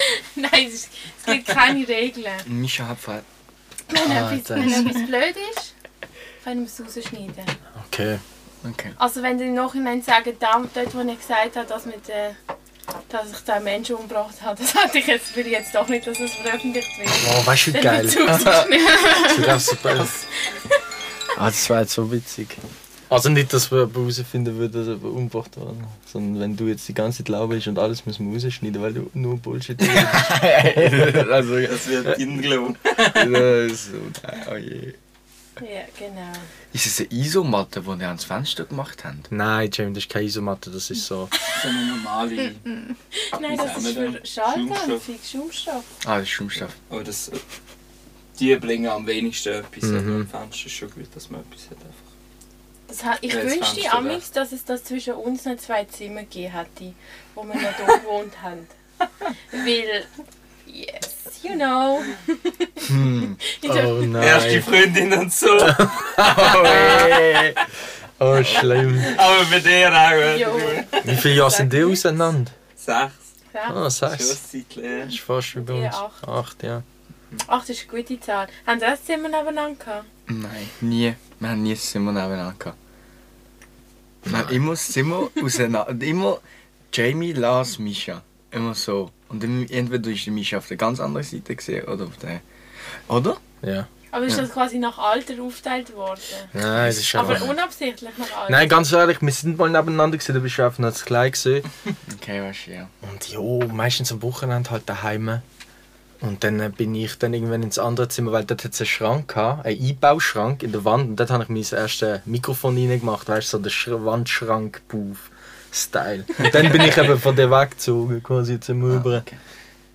Sie reflektieren über ihre Beziehung, philosophieren über Musik und erzählen ungefiltert über ihre Höhen und Tiefen. Der Vibe im Studio mit Kaffi und Gipfeli war sehr entspannt, lass dich anstecken.